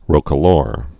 (rōkə-lôr, rŏkə-)